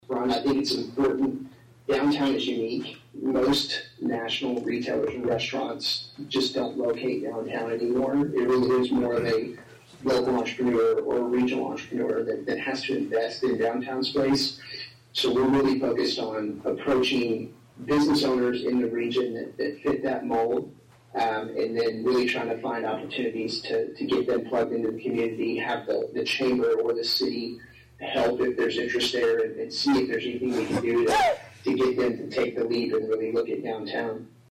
appeared via zoom at Wednesday’s Atlantic City Council meeting and provided an update on business recruitment.